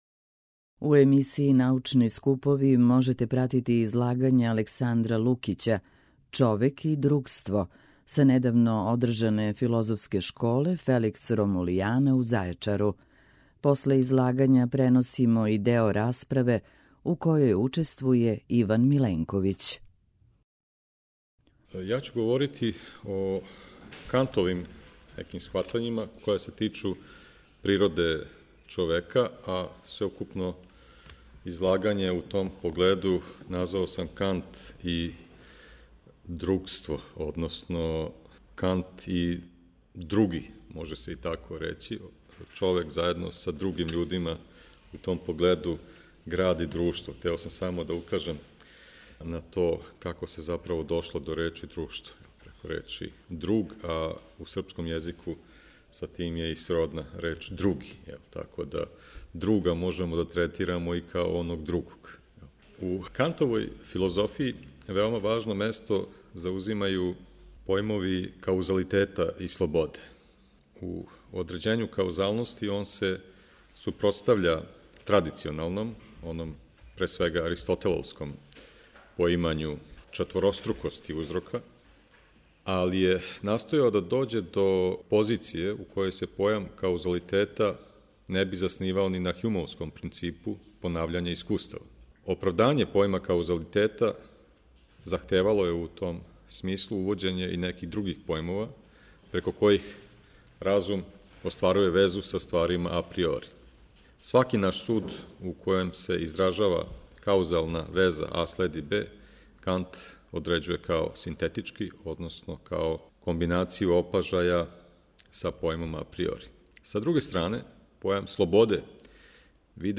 Научни скупови
Редакција Преносимо излагања са научних конференција и трибина.